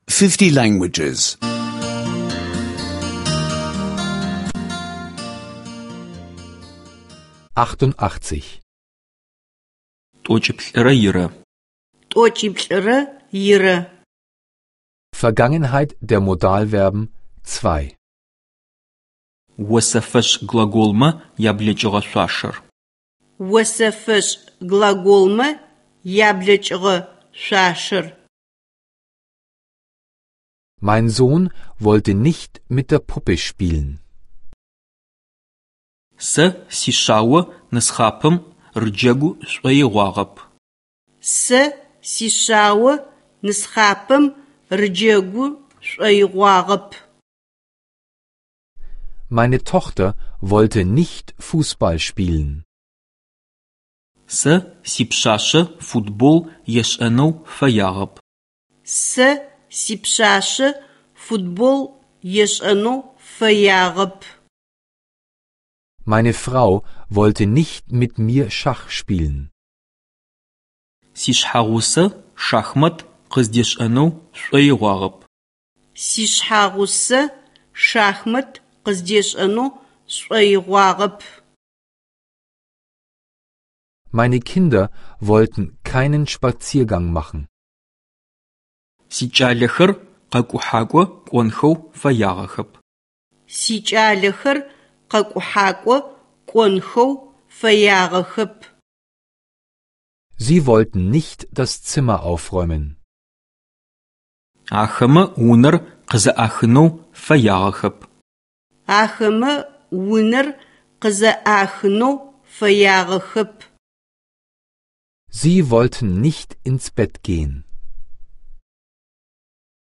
Adygeanischer Audio-Lektionen, die Sie kostenlos online anhören können.